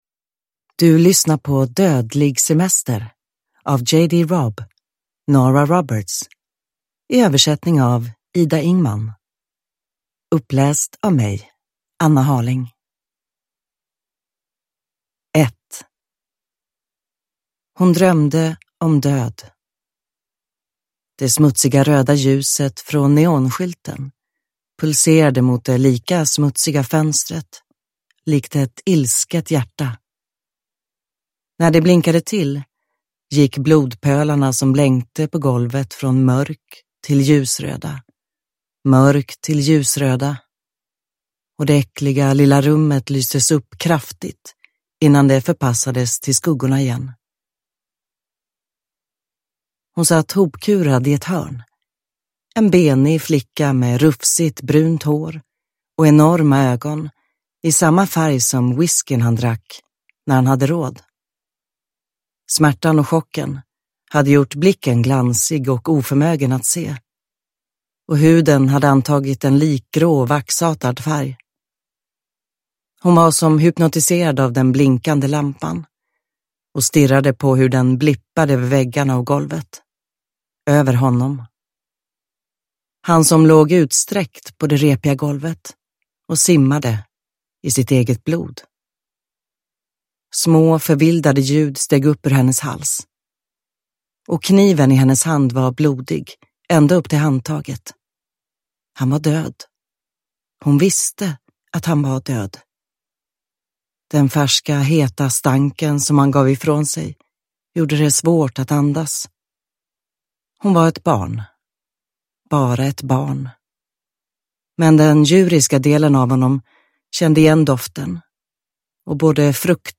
Dödlig semester (ljudbok) av Nora Roberts